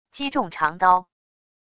击中长刀wav音频